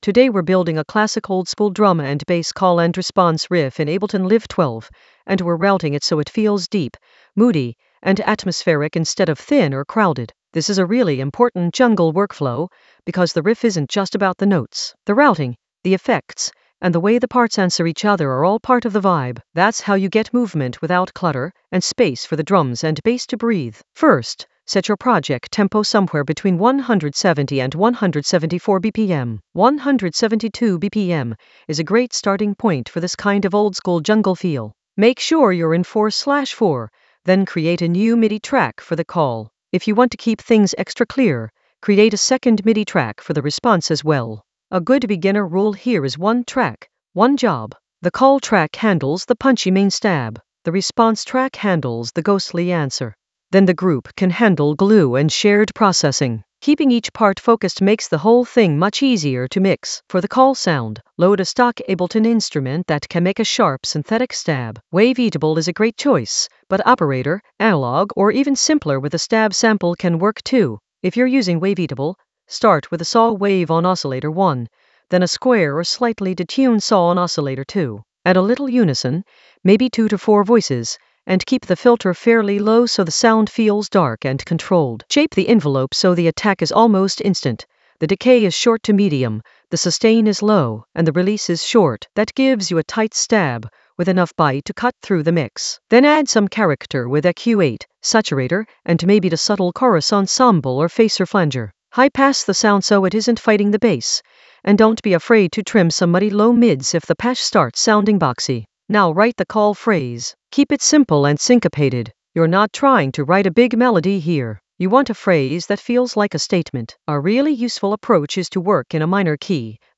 An AI-generated beginner Ableton lesson focused on Route oldskool DnB call-and-response riff for deep jungle atmosphere in Ableton Live 12 in the Workflow area of drum and bass production.
Narrated lesson audio
The voice track includes the tutorial plus extra teacher commentary.